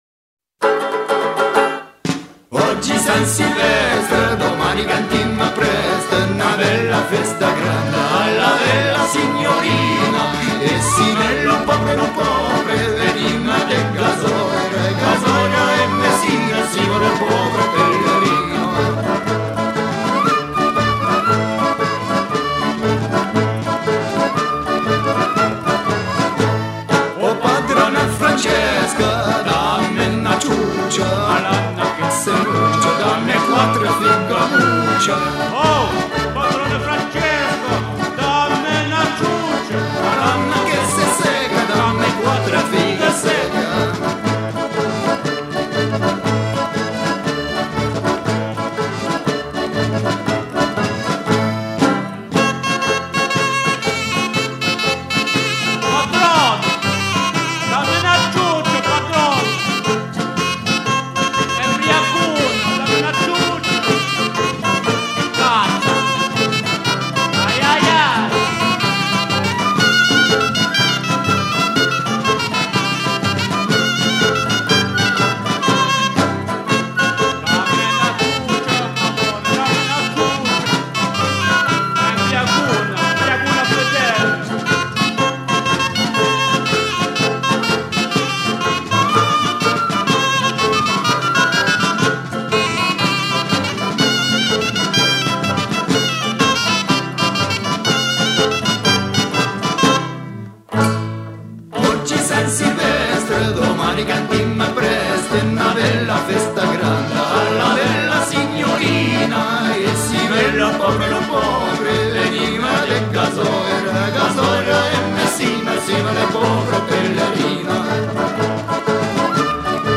circonstance : quête calendaire
Genre strophique
Pièce musicale éditée